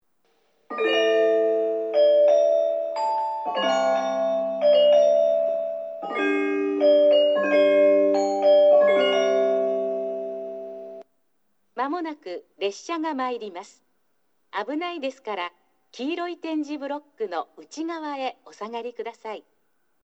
1番のりば接近放送　女声
（慈眼寺行き）   2019年10月27日より、シンガーソングライターである吉田拓郎氏作曲の「夏休み」が接近メロディとして流れるようになりました。
メロディに続いて放送は1回のみ流れます。
スピーカーはJVCラインアレイのまま変更ありません。